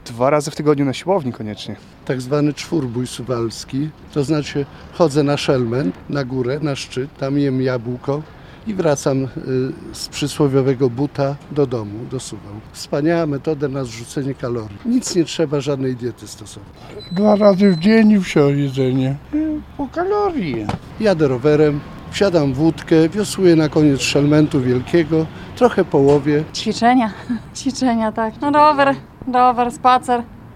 O to jak poradzić sobie z nadmiarem świątecznych kalorii zapytaliśmy mieszkańców Suwałk. Najpopularniejsza jest aktywność fizyczna na świeżym powietrzu, ale też siłownia.